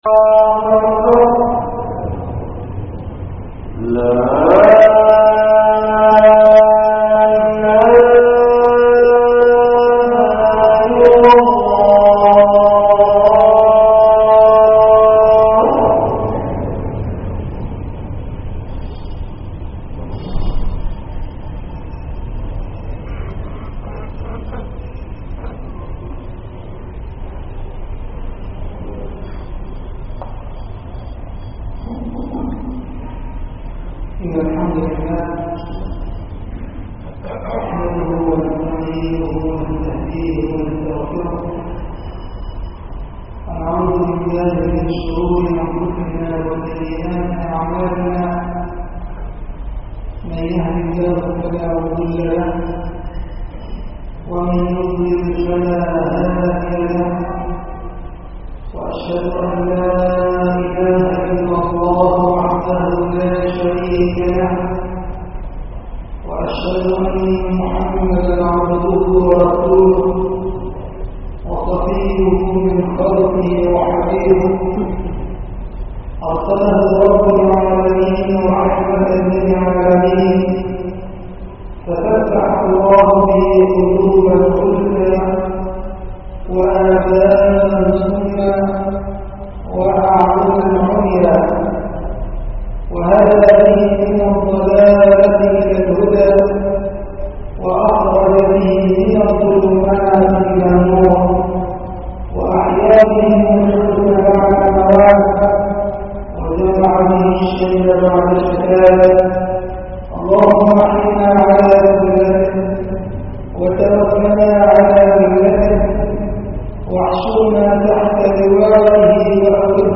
خطبة الجمعة